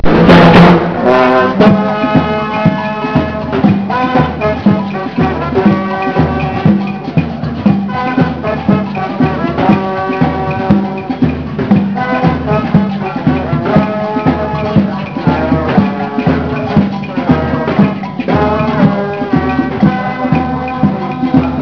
9:00 Uhr Frühstückslauf.
Samba auch beim Frühstückslauf,